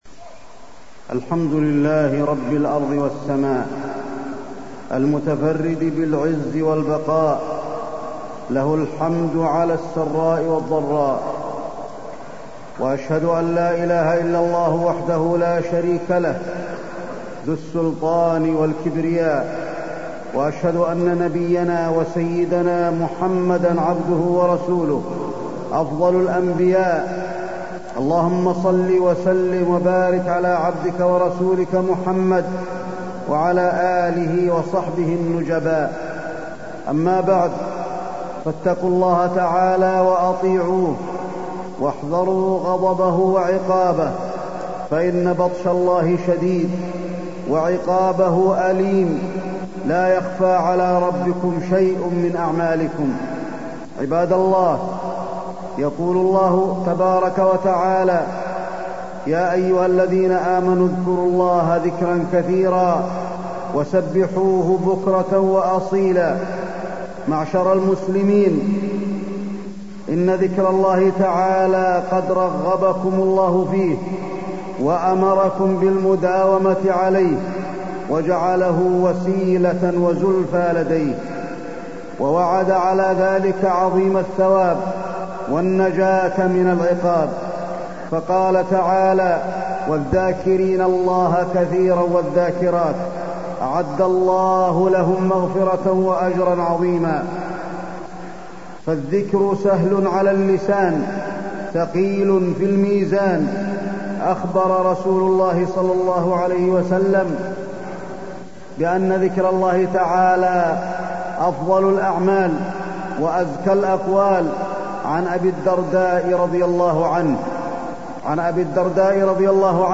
تاريخ النشر ٢٨ جمادى الآخرة ١٤٢٣ هـ المكان: المسجد النبوي الشيخ: فضيلة الشيخ د. علي بن عبدالرحمن الحذيفي فضيلة الشيخ د. علي بن عبدالرحمن الحذيفي الذكر وفوائده